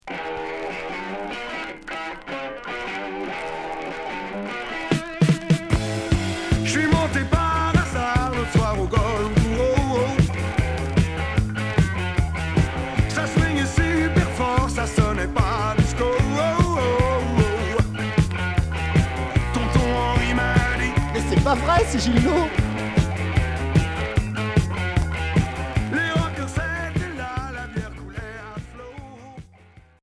Rock Unique 45t